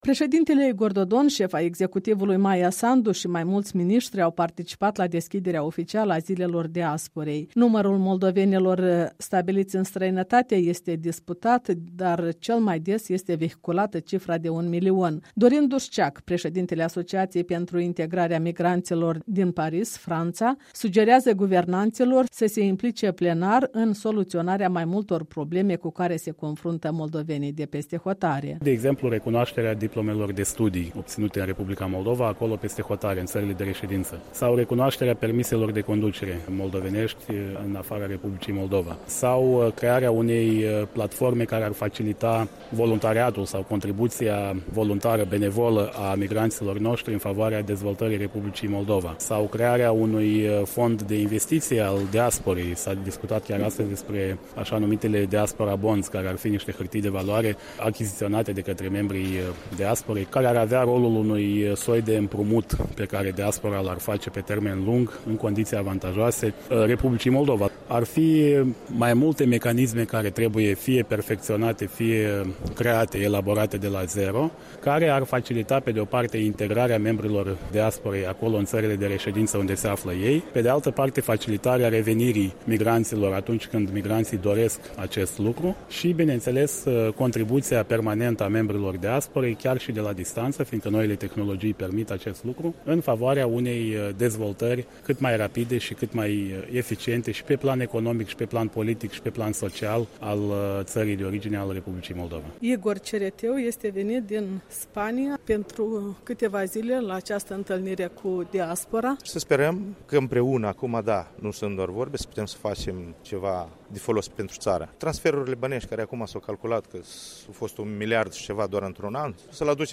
Zilele diasporei la Palatul Republicii
De vorbă cu mai mulți moldoveni stabiliți peste hotare care au participat astăzi la deschiderea Zilelor diasporei.